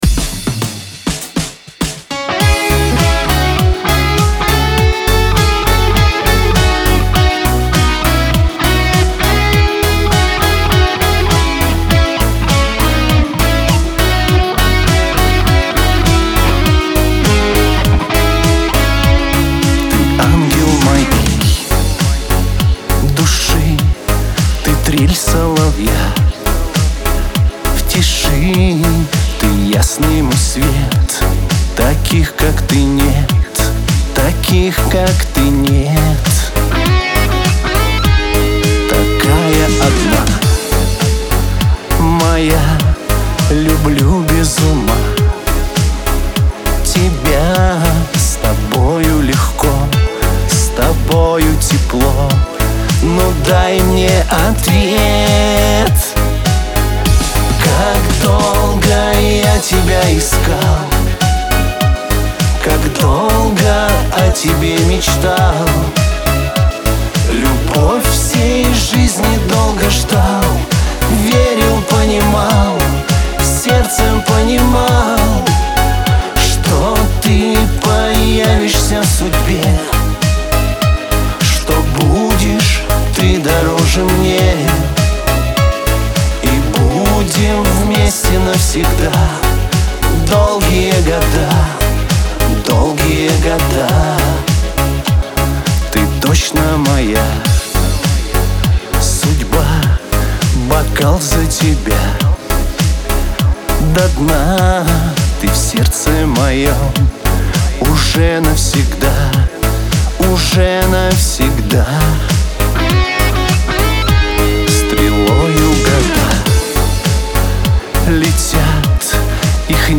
диско , pop